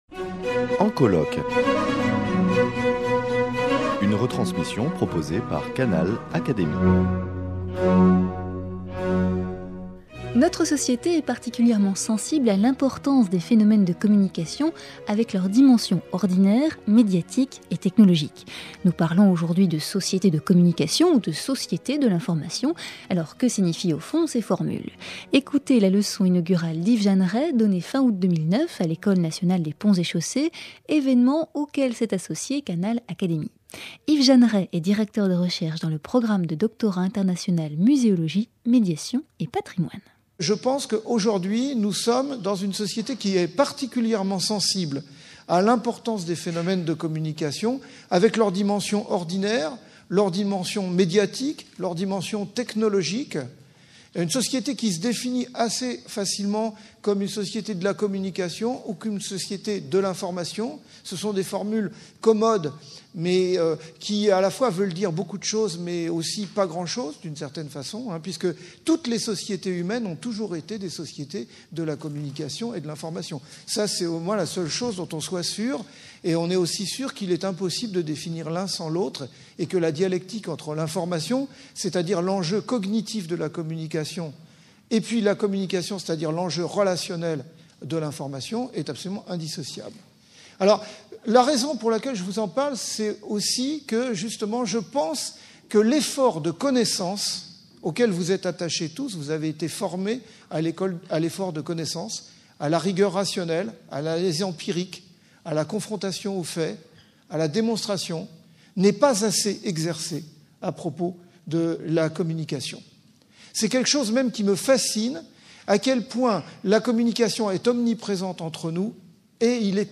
leçon inaugurale
donnée fin août 2009 à l’Ecole Nationale des Ponts et Chaussées